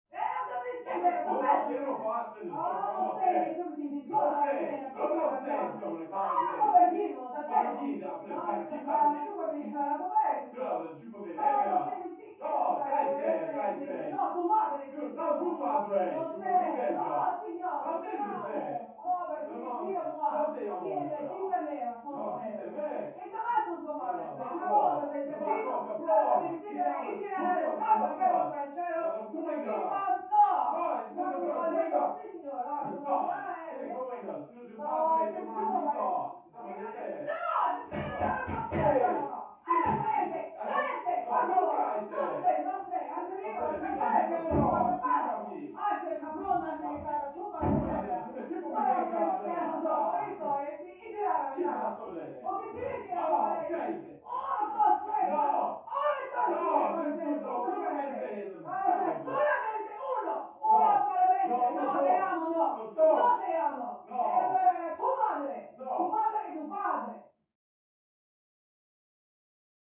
Argue; Could Be Spanish-italian Couple Yelling At Each Other, From Down Hallway.